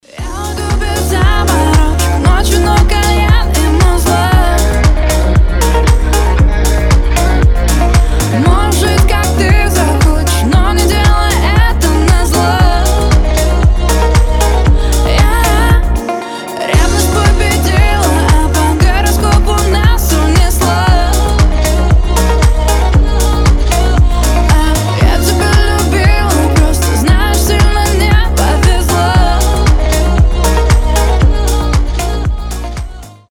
• Качество: 320, Stereo
мелодичные
легкие